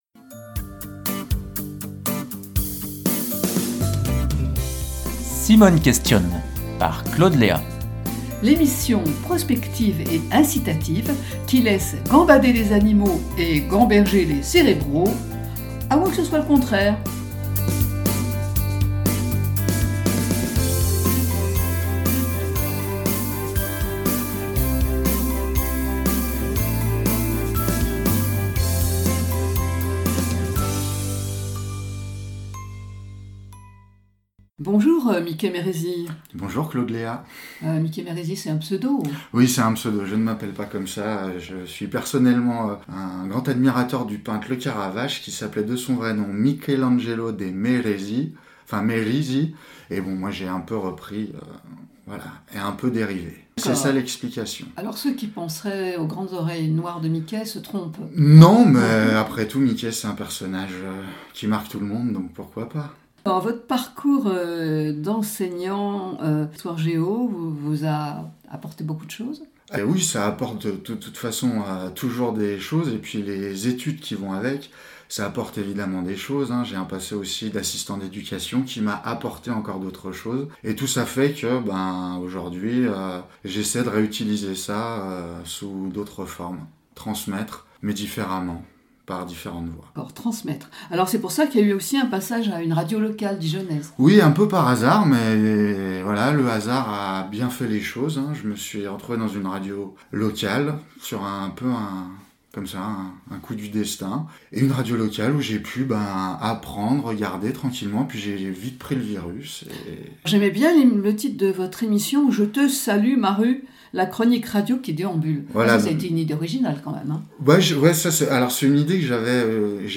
Webradio dijonnaise ...